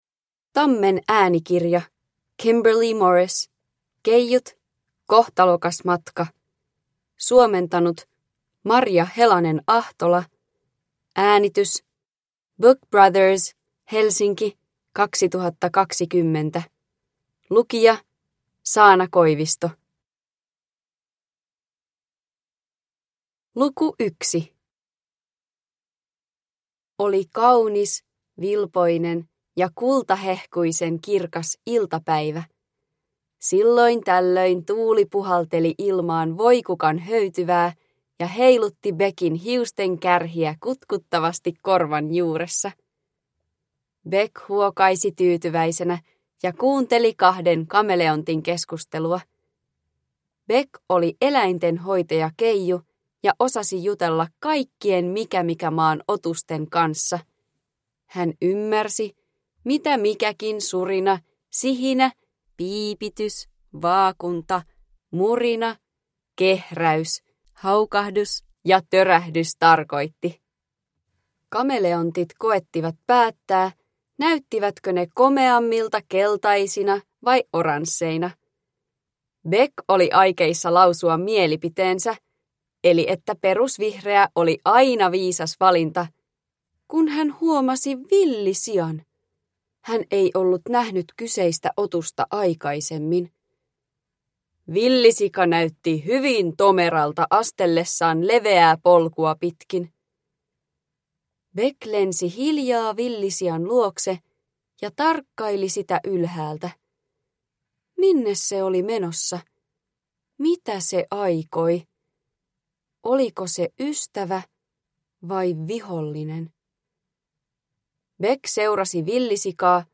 Keijut. Kohtalokas matka – Ljudbok – Laddas ner